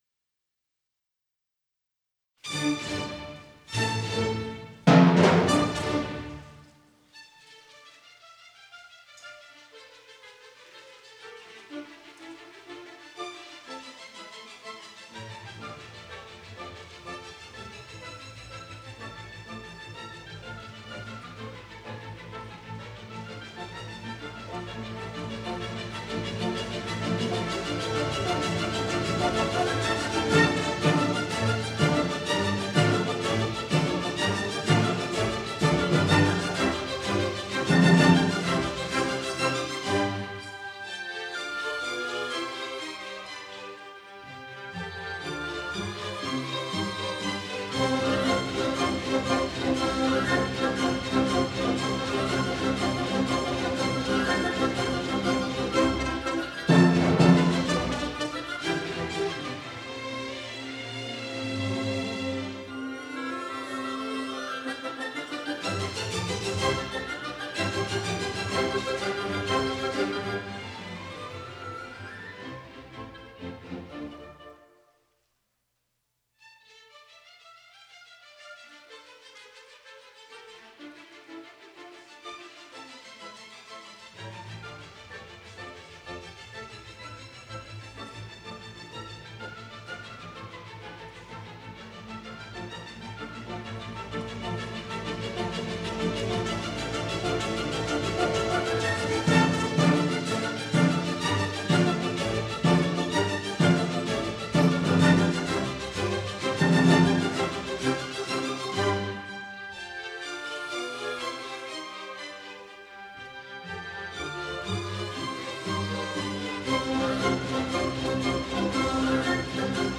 SATB Chorus
Soprano
Mezzo Soprano
Tenor
Bass-Baritone
Recorded Live at Boston Symphony Hall